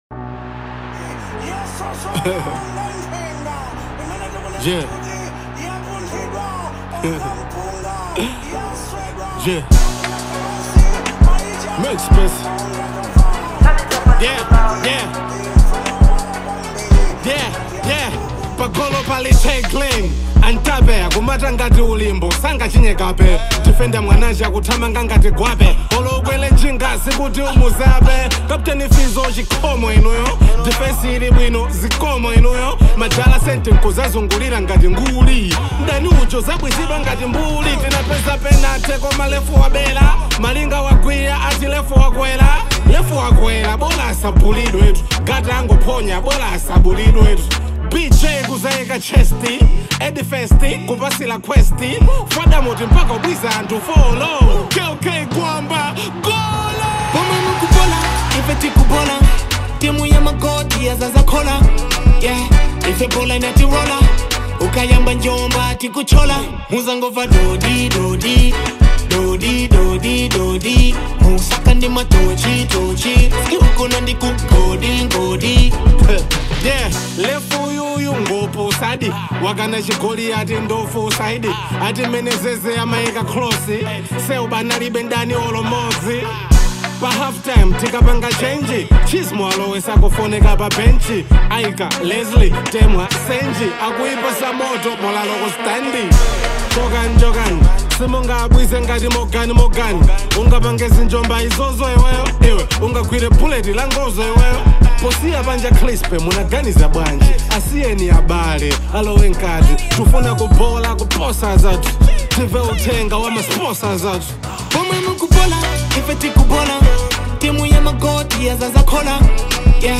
Genre : Hip Hop